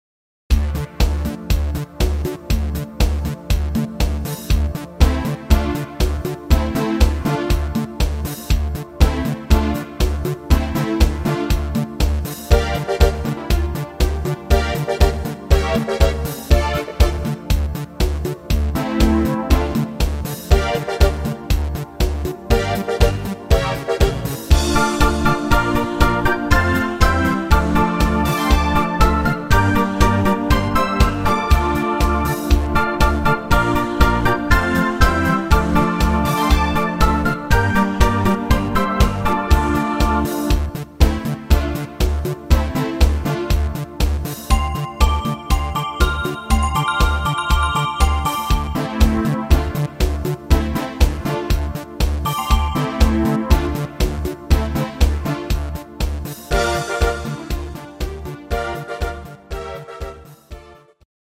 Latin-Standard